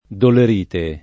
dolerite
dolerite [ doler & te ]